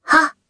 Kara-Vox_Attack1_jp.wav